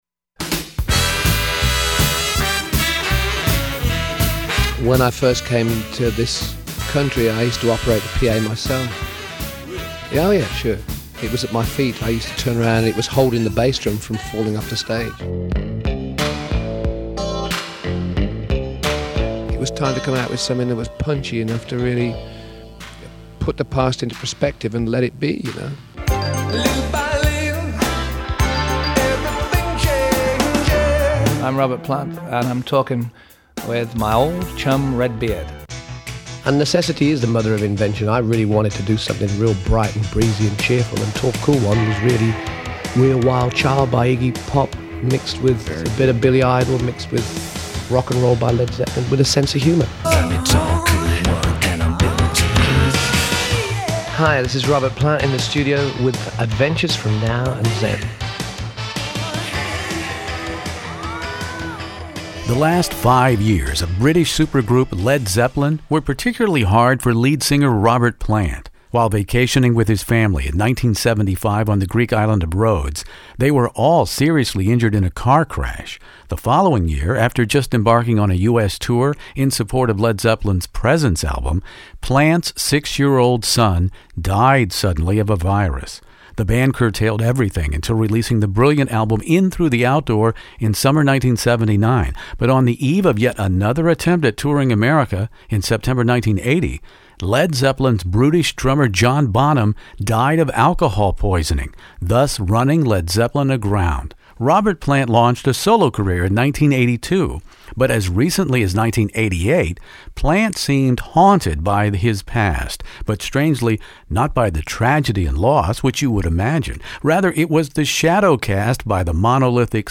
Robert Plant Shaken 'n' Stirred interview